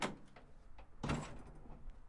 皮卡 " 皮卡 福特62门打开
描述：1962年福特皮卡车门打开
Tag: 打开 卡车 皮卡